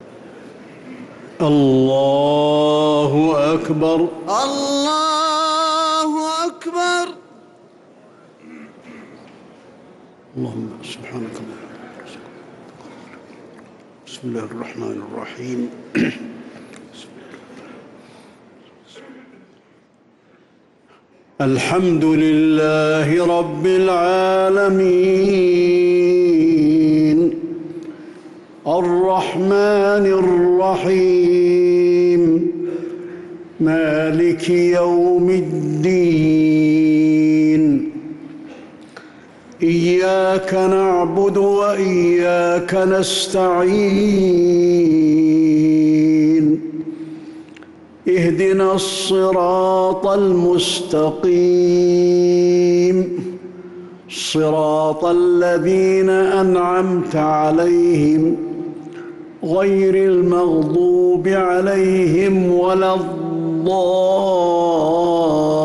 صلاة المغرب للقارئ علي الحذيفي 24 رمضان 1445 هـ
تِلَاوَات الْحَرَمَيْن .